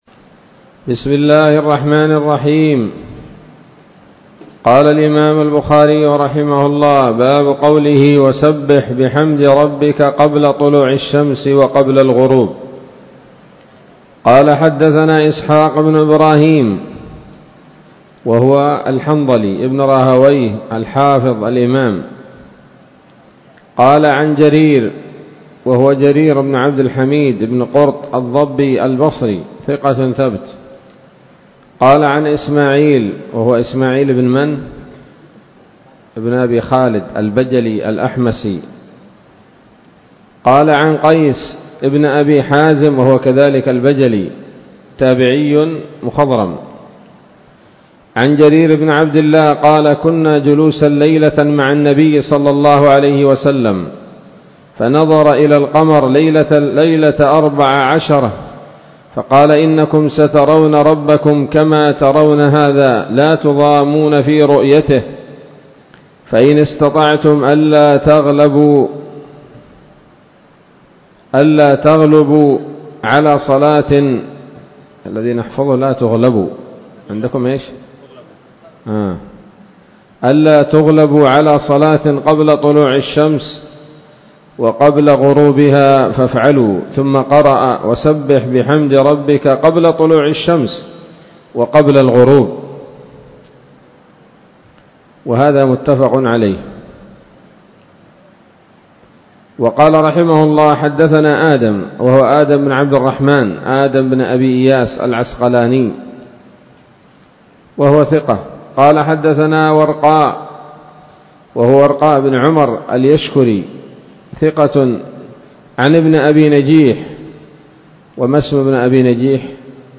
الدرس التاسع والثلاثون بعد المائتين من كتاب التفسير من صحيح الإمام البخاري